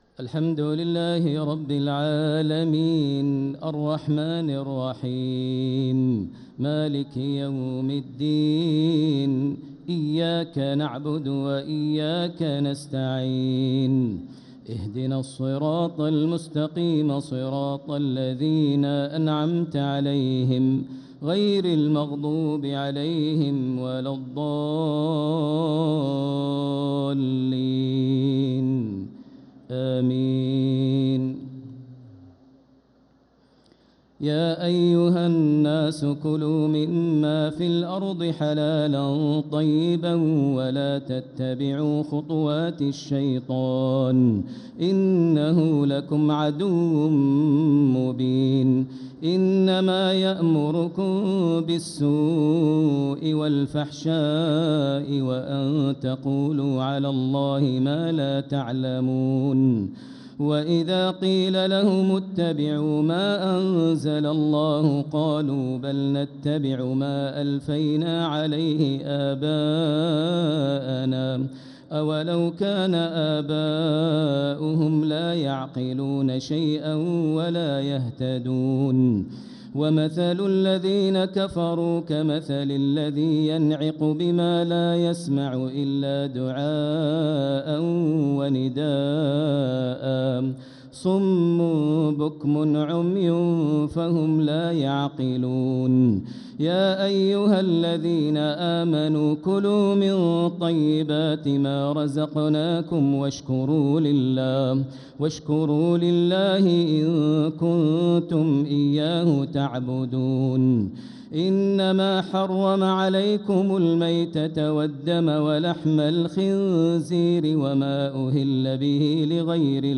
تراويح ليلة 2 رمضان 1446هـ من سورة البقرة ( 168-203 ) | Taraweeh 2nd night Ramadan 1446H > تراويح الحرم المكي عام 1446 🕋 > التراويح - تلاوات الحرمين